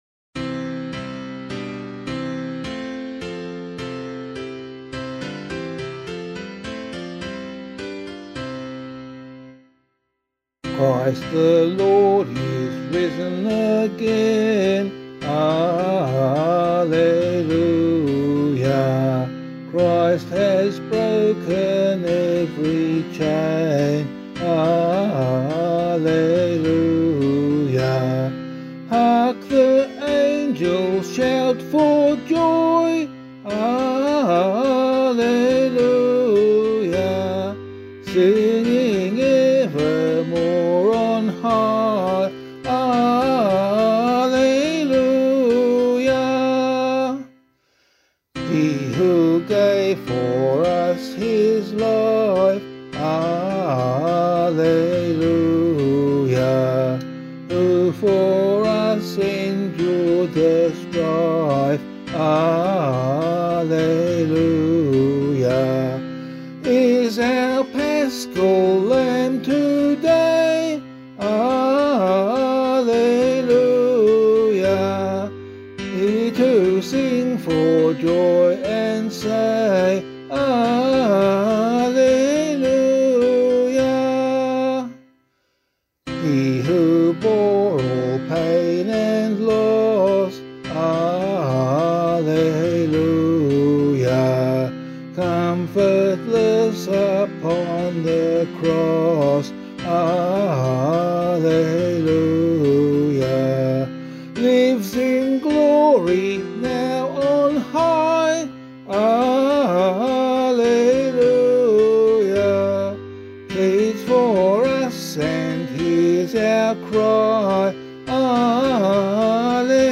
vocal
Christ the Lord Is Risen Again [Winkworth - EASTER HYMN] - vocal [DO74].mp3